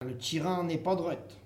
Sallertaine
Catégorie Locution